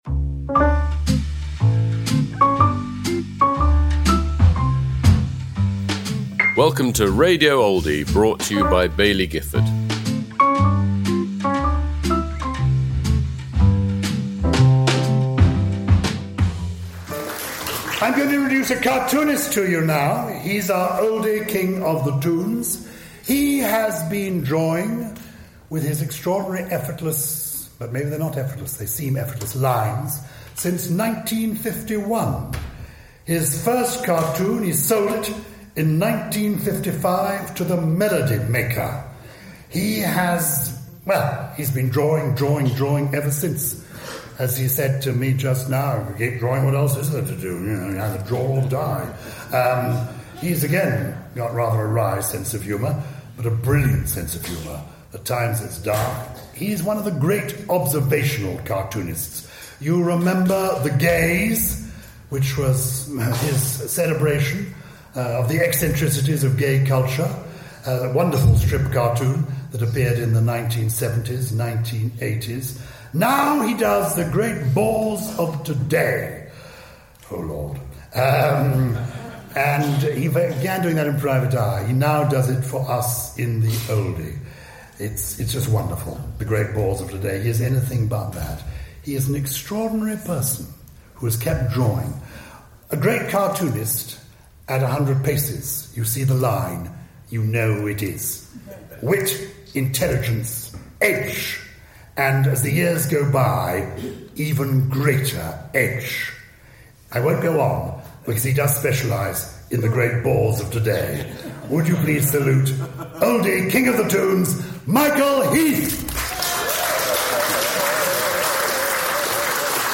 Michael Heath at the 2025 Oldie of the Year Awards
Introduced by Gyles Brandreth.